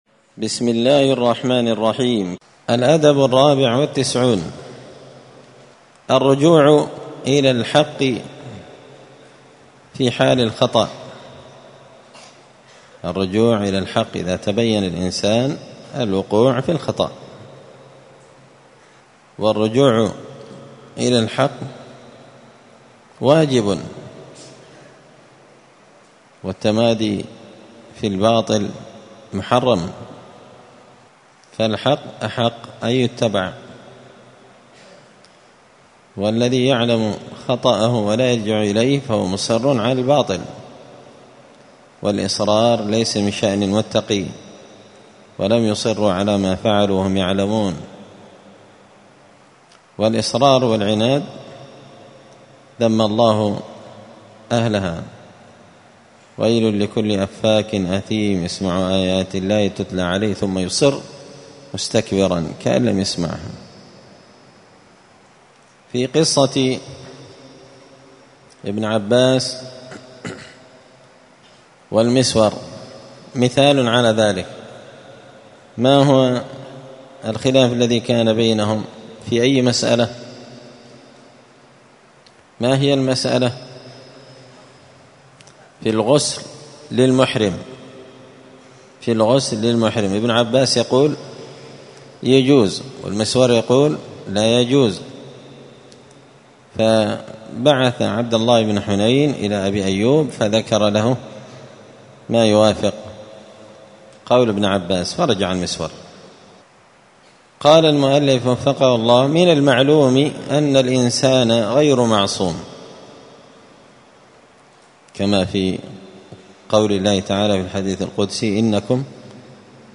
*الدرس السادس بعد المائة (106) الأدب الرابع والتسعون الرجوع إلى الحق عند معرفة الخطأ*
مسجد الفرقان قشن_المهرة_اليمن